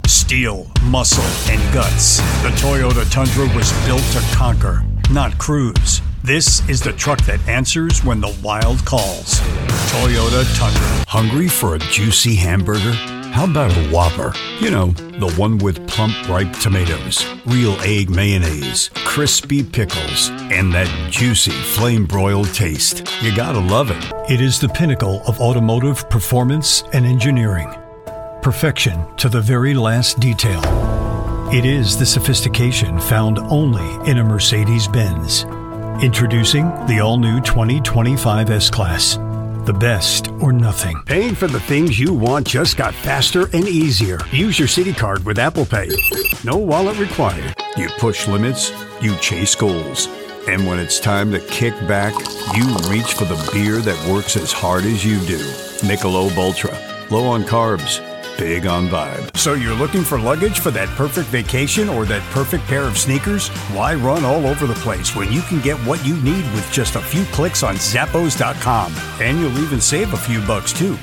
Commercial VO Demo